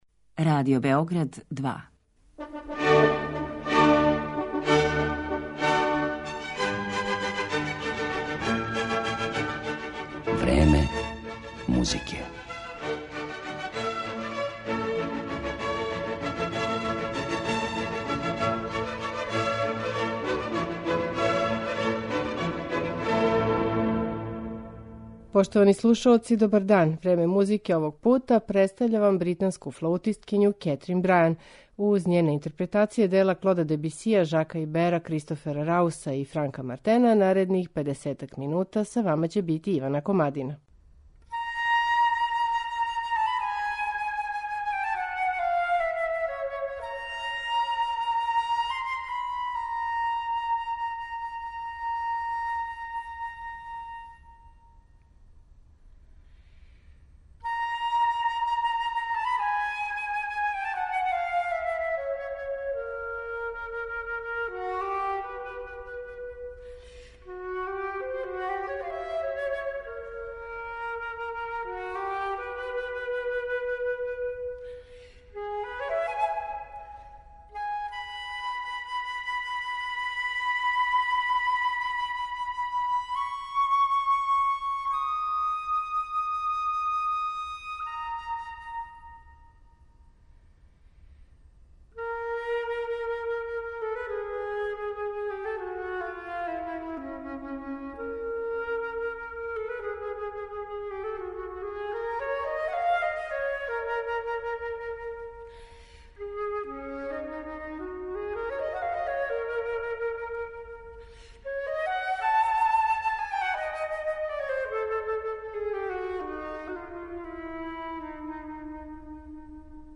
флаута